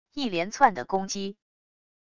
一连窜的攻击wav音频